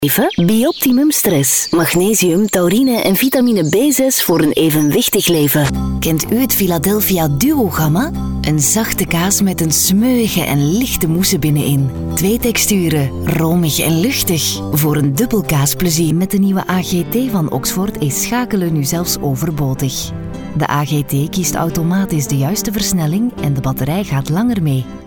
Native speaker Female 20-30 lat
Nagranie lektorskie